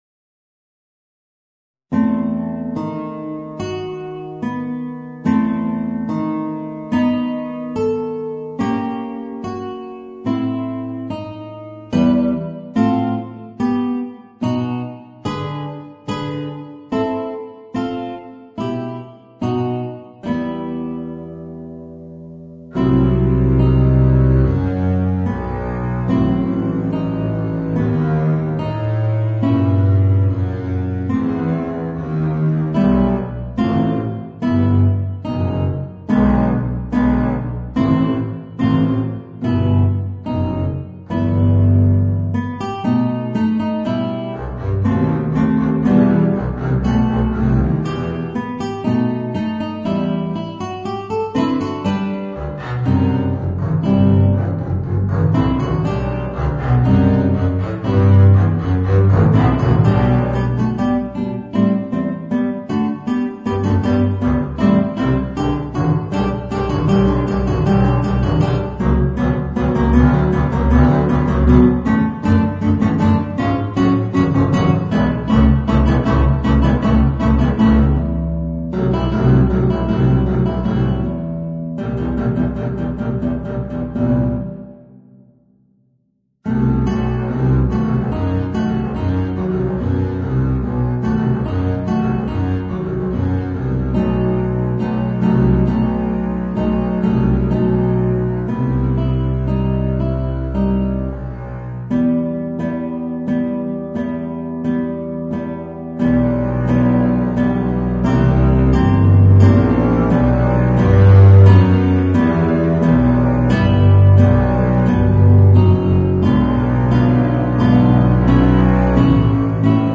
Voicing: Guitar and Contrabass